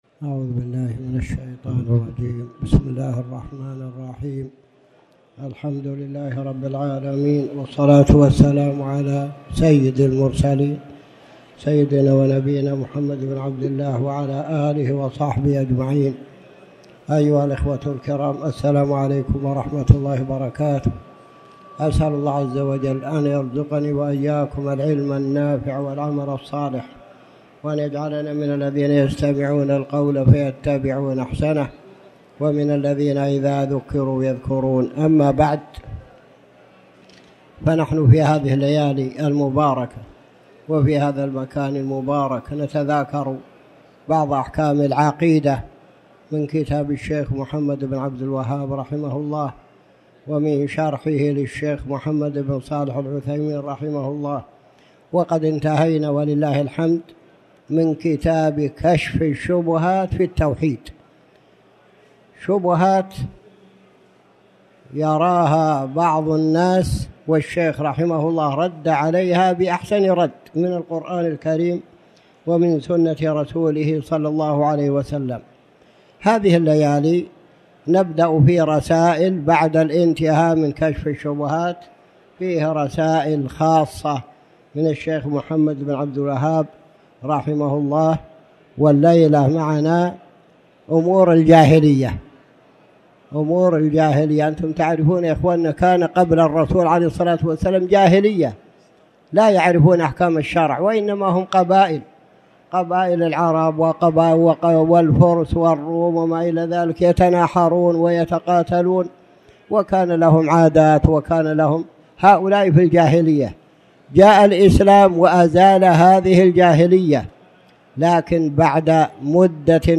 تاريخ النشر ١٧ جمادى الآخرة ١٤٣٩ هـ المكان: المسجد الحرام الشيخ